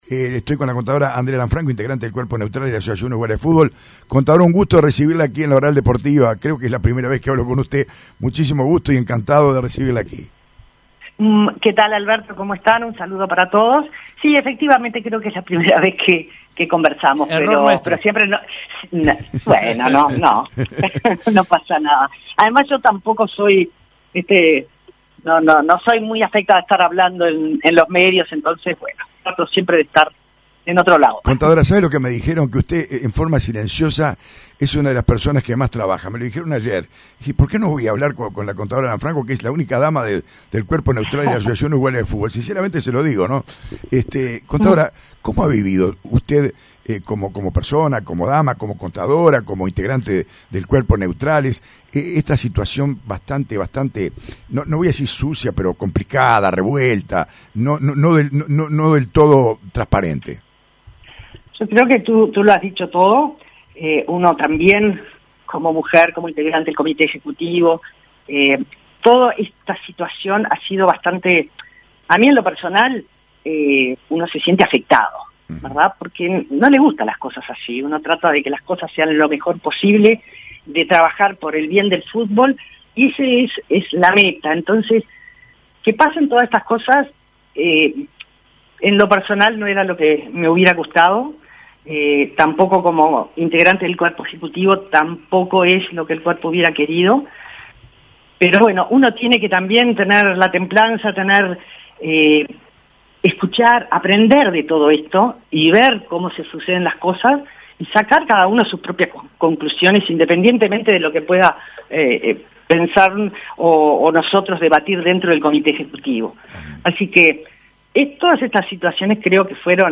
pasó este viernes por los micrófonos de La Oral Deportiva y nos dejó los siguientes conceptos: